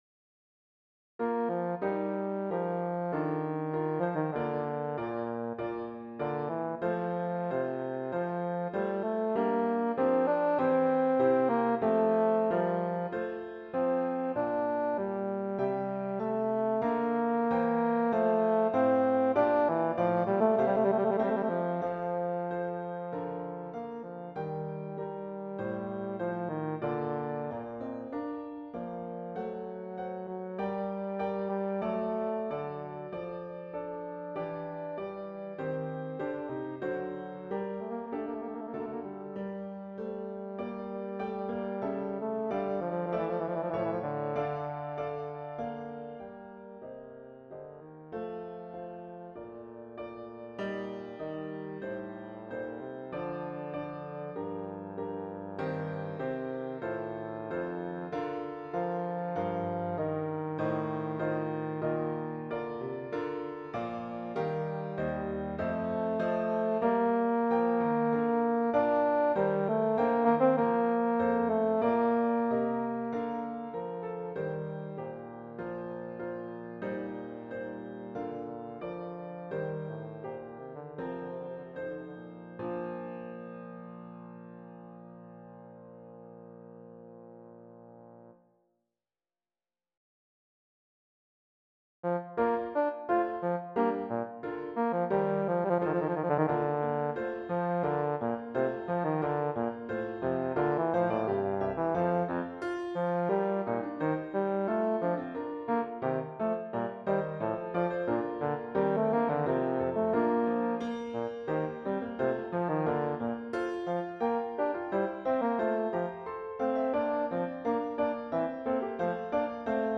Voicing: Euphonium